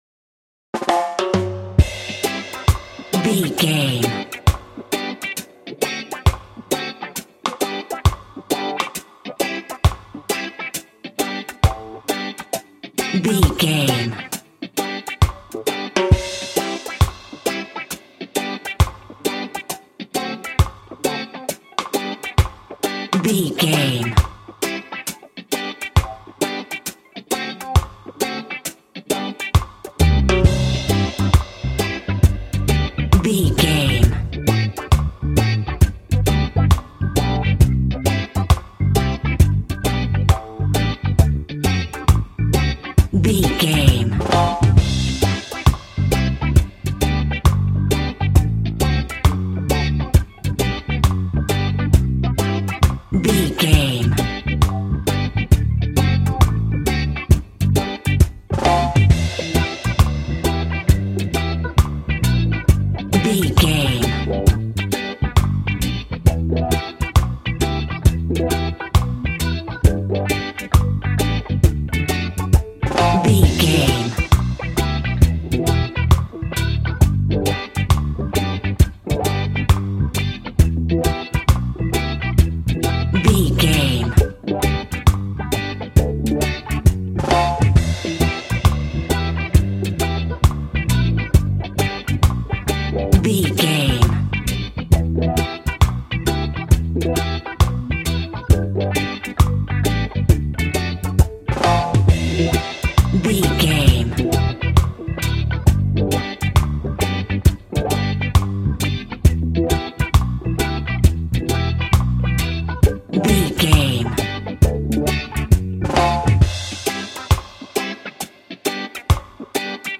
Ionian/Major
cheerful/happy
mellow
fun
drums
electric guitar
percussion
horns
electric organ